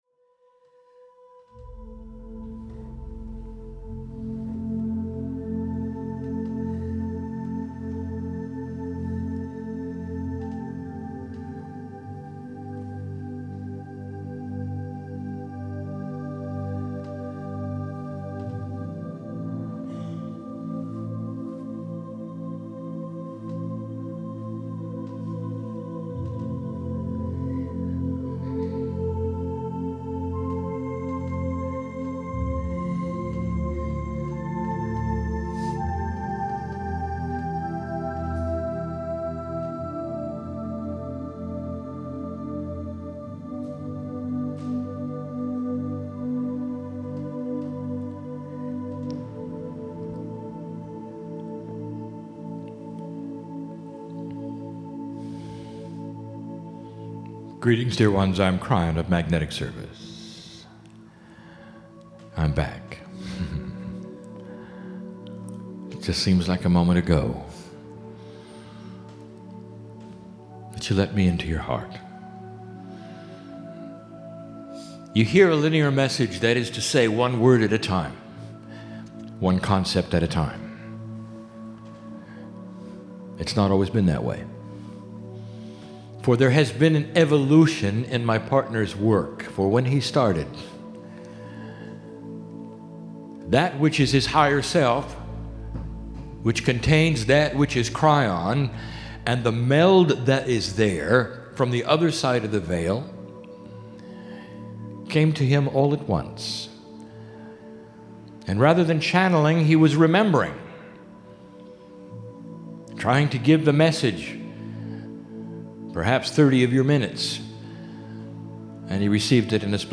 INSTRUCTIONS: 49 megabytes 36 minutes High-quality Stereo - MP3 Filename: "Buffalo_10.mp3" PC - Right-click the left image to download the file.